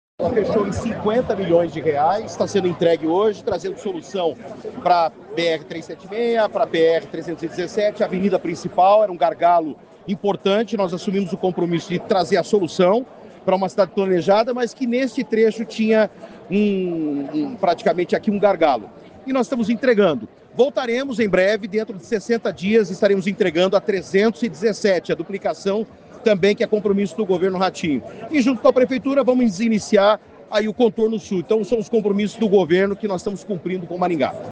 O secretário de Infraestrutura e Logística do Paraná, Sandro Alex, disse, em coletiva de imprensa sobre a entrega da obra do Trevo do Catuaí, que a próxima obra que será entregue será a duplicação da PR-317, entre Maringá e Iguaraçu.